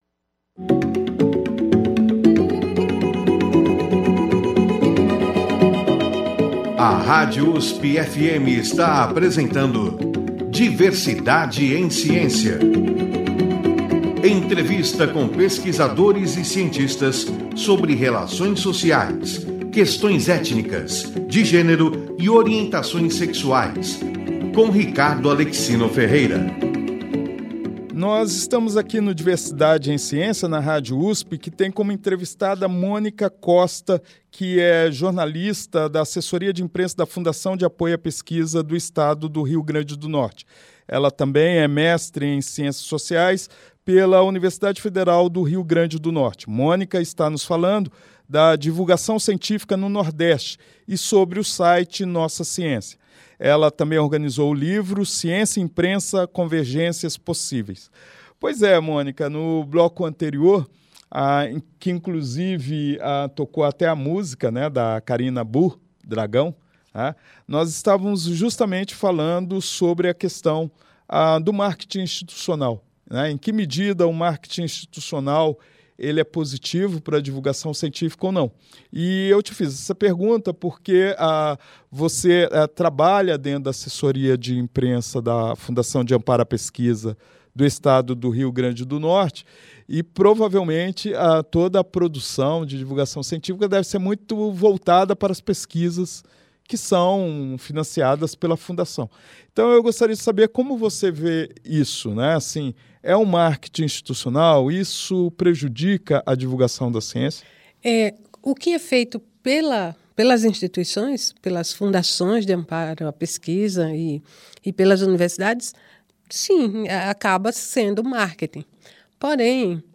Na entrevista